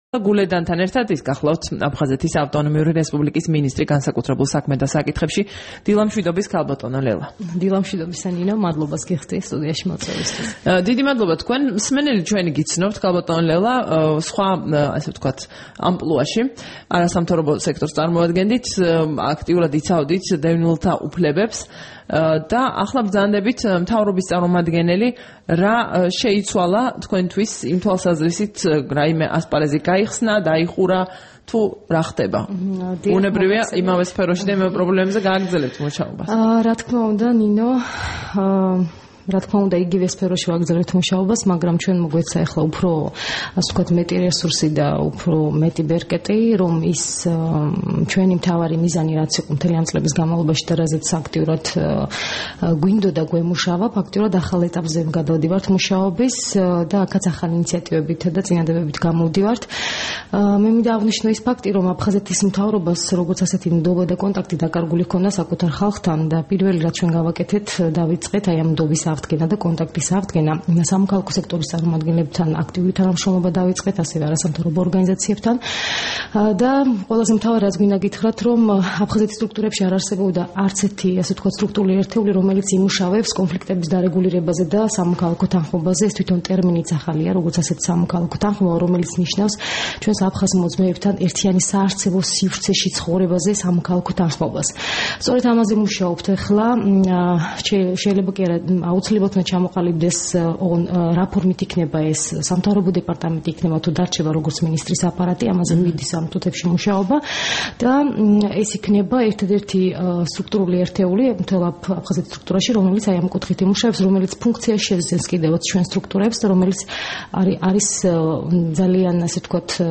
30 ოქტომბერს რადიო თავისუფლების დილის გადაცემის სტუმარი იყო ლელა გულედანი, აფხაზეთის ავტონომიური რესპუბლიკის მინისტრი განსაკუთრებულ საქმეთა საკითხებში.
საუბარი ლელა გულედანთან